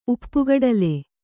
♪ uppugaḍale